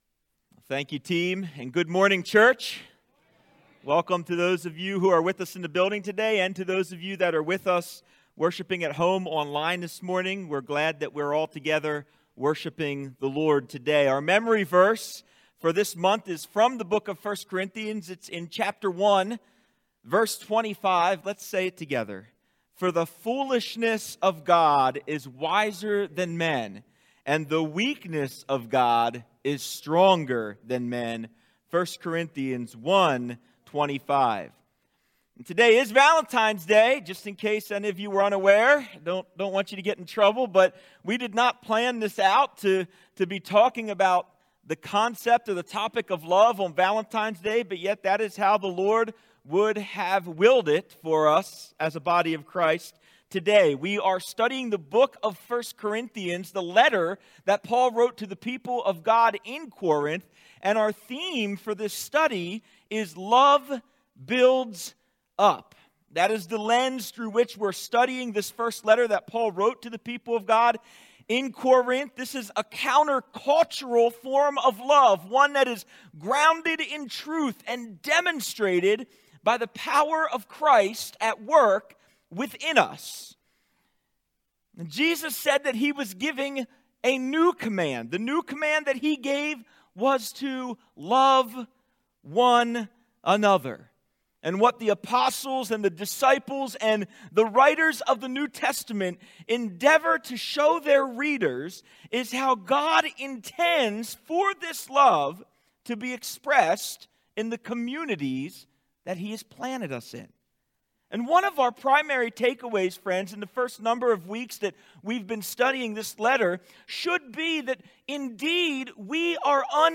1 Corinthians 13:8-13 Sermon